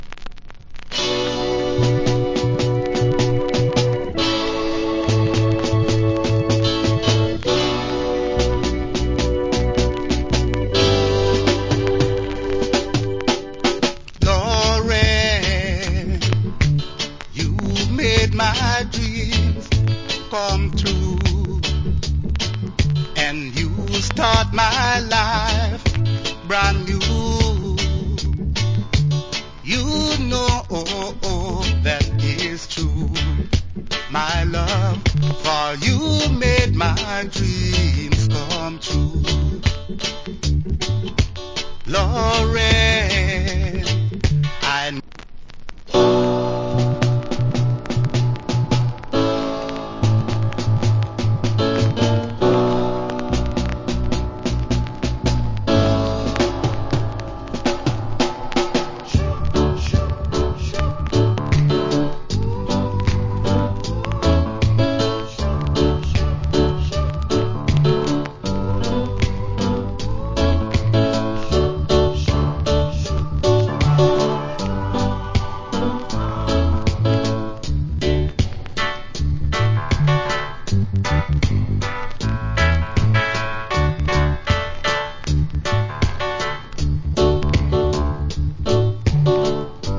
Nice Reggae.